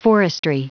Prononciation du mot forestry en anglais (fichier audio)
Prononciation du mot : forestry
forestry.wav